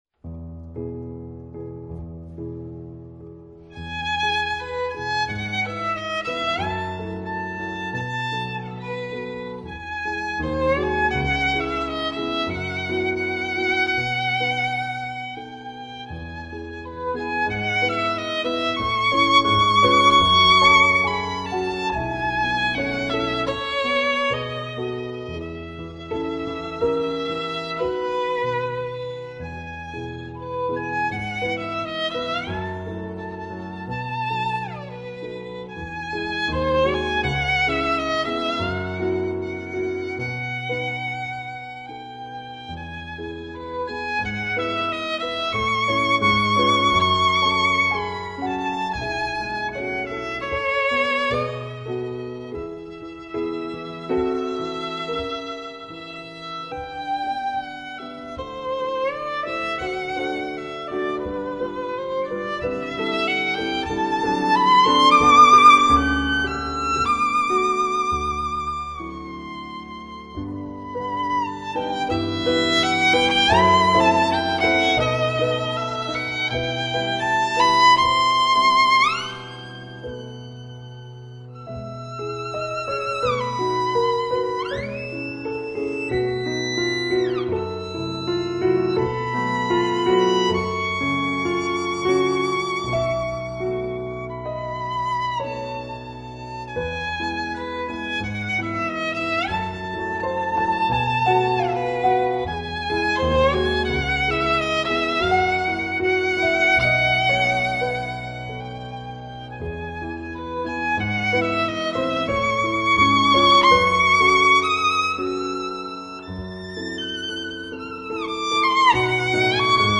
那深情的旋律，恰似来自恋人的绵绵情话。在柔美的曲调中，又有一丝哀怨的情调。经过带有复杂情绪的尾声，乐曲逐渐减弱而终了，仿佛是恋人还在喃喃私语……
音乐甜蜜温馨，旋律温婉动人，让人一听倾情。